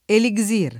elisir [ eli @& r ] o elixir [ eli g@& r o elik S& r ] s. m.